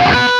LEAD B 3 LP.wav